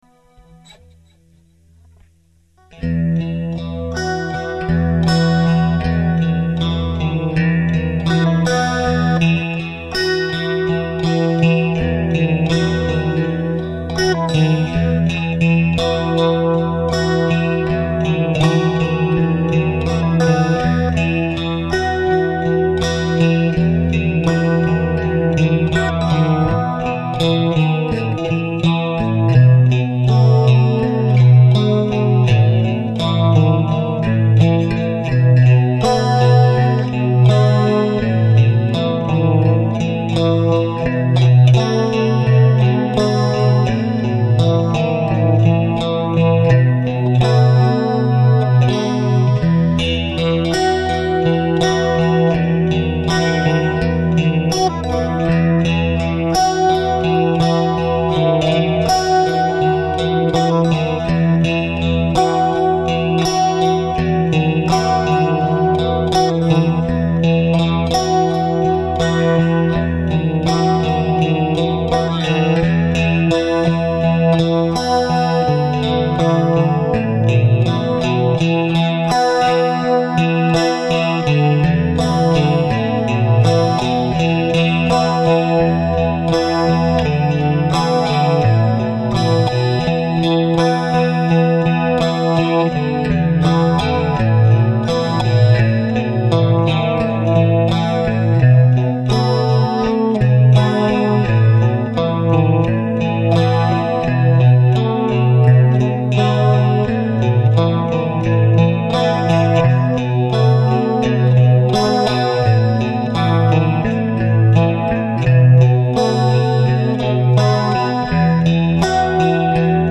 teal heres a new one, again its increadibly rought. its just an idea i wanted to record so i wouldnt forget!